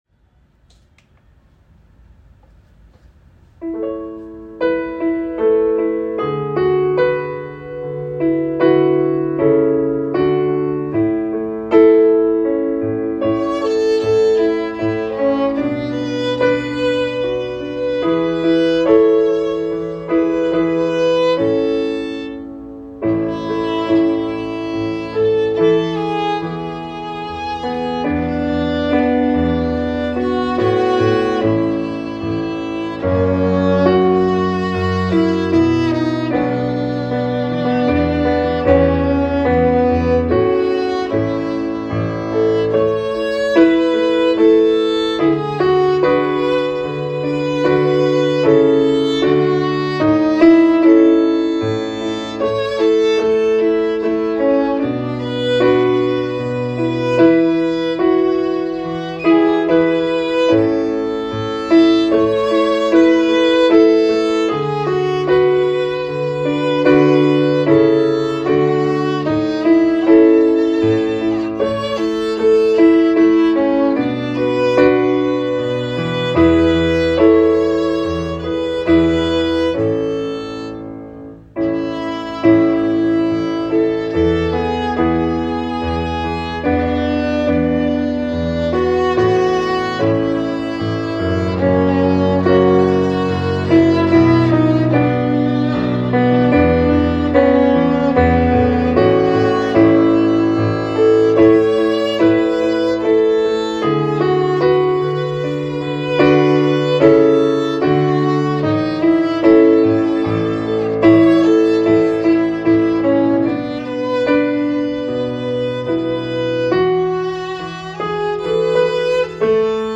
Sankta Lucia i A-dur (Piano och melodistämma på fiol)
Sankta-Lucia-m-fiol_A.m4a